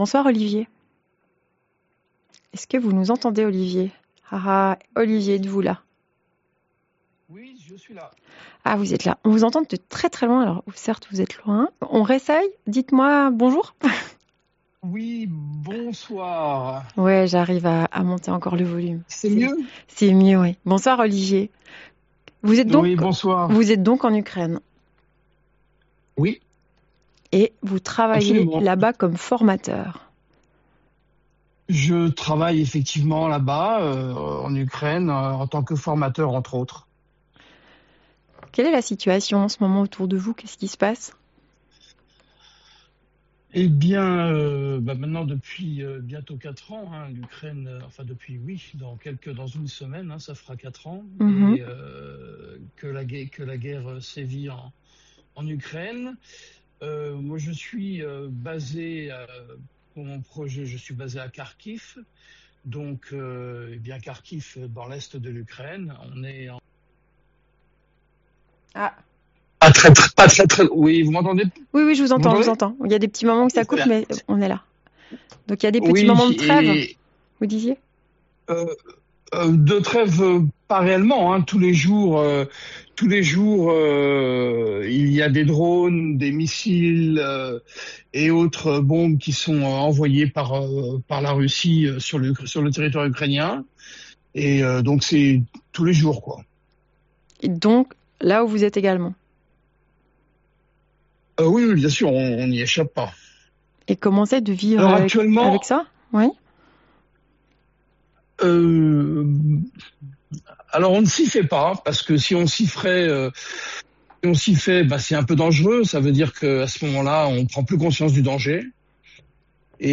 © Interview diffusée sur RTS La Première, dans l’émission La Ligne de cœur